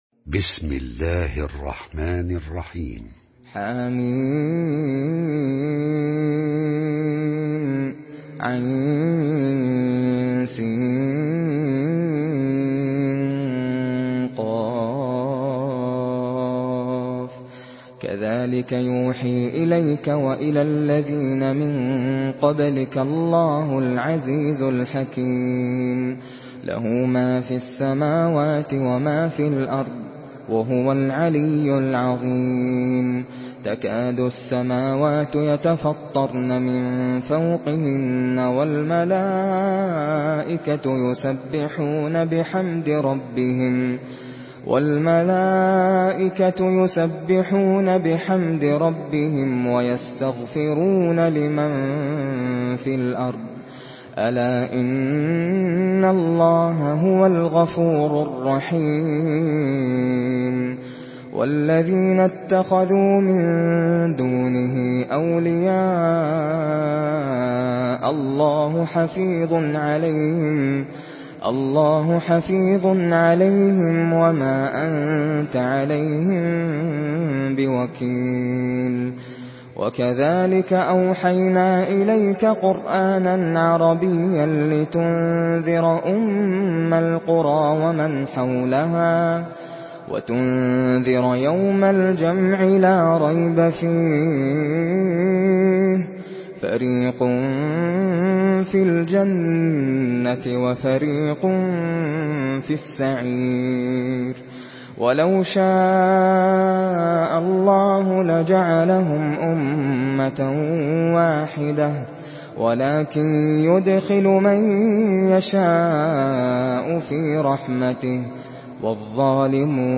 Surah Şura MP3 in the Voice of Nasser Al Qatami in Hafs Narration
Surah Şura MP3 by Nasser Al Qatami in Hafs An Asim narration.
Murattal Hafs An Asim